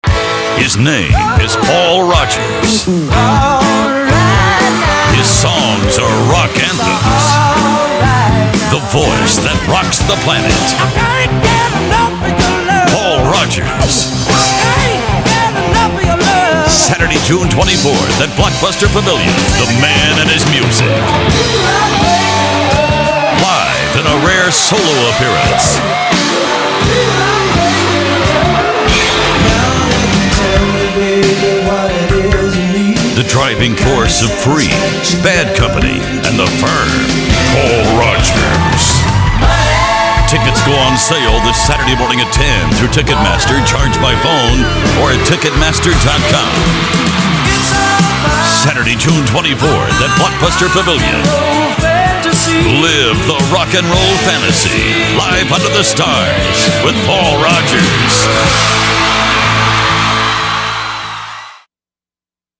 rock anthems